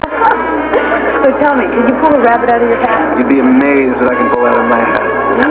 These wavs were taken directly from the ABC broadcasts.